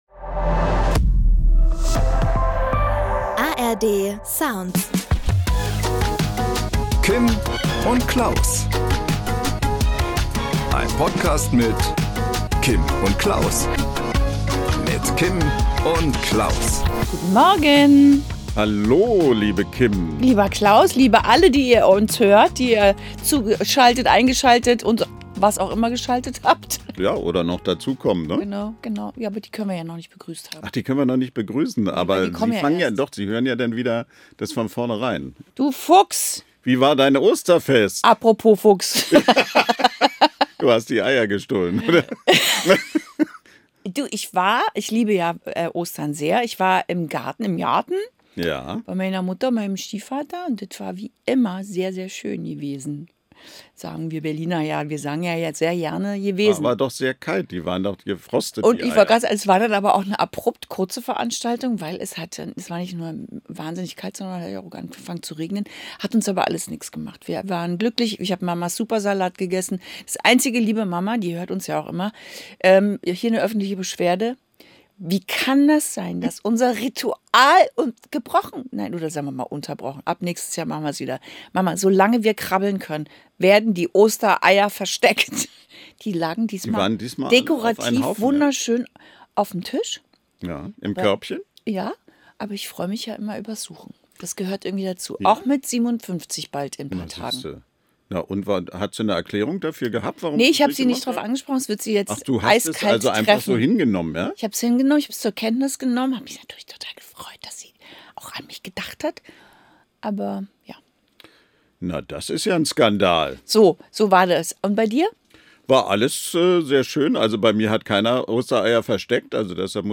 Am Promi-Telefon: Star-Sänger und Stil-Ikone Max Raabe. Und er verrät: Trägt er wirklich auch mal ne Jogginghose?